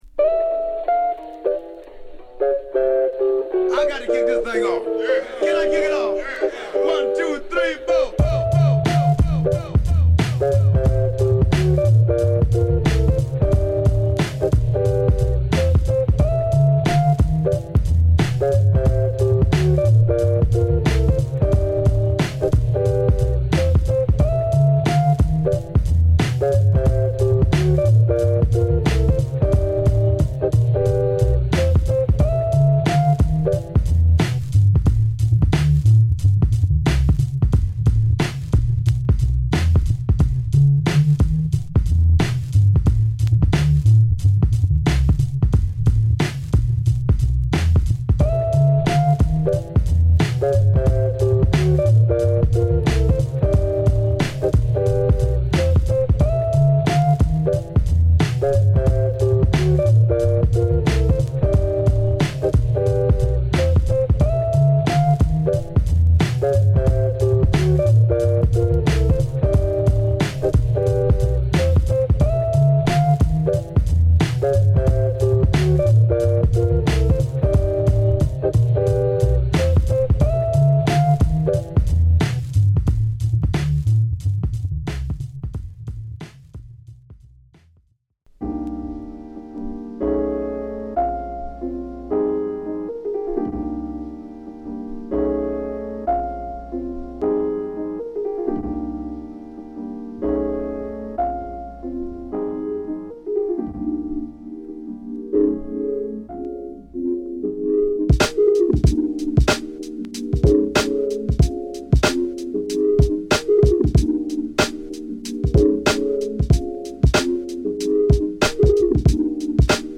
カリフォルニアの緩いブレイクビーツと都会的なジャズが融合した心地よいアルバム。